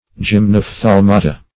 Search Result for " gymnophthalmata" : The Collaborative International Dictionary of English v.0.48: Gymnophthalmata \Gym`noph*thal"ma*ta\ (j[i^]m`n[o^]f*th[a^]l"m[.a]*t[.a]), n. pl.
gymnophthalmata.mp3